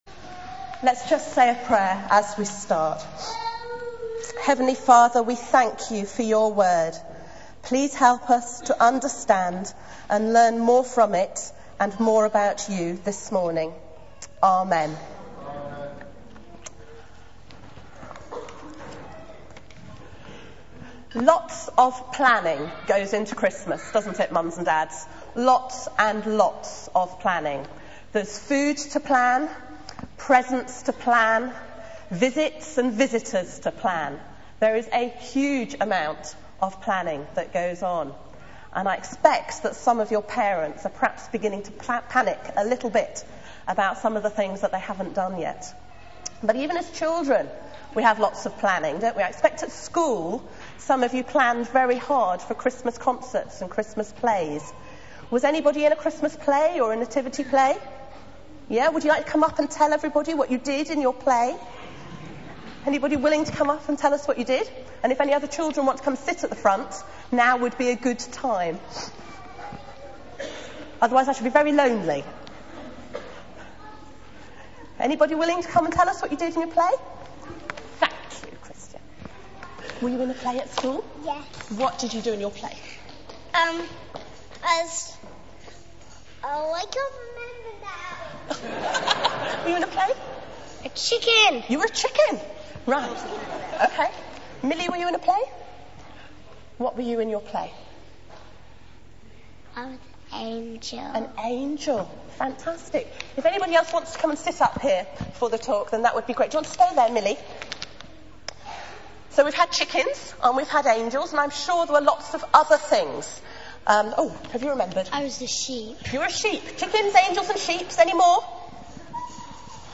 Media for 9:15am Service on Sun 21st Dec 2008 09:15 Speaker: Passage: Matthew 1 v18-25 Series: All Age Carol Service Theme: Family Planning Talk Search the media library There are recordings here going back several years.